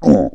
spawners_mobs_mummy_hit.1.ogg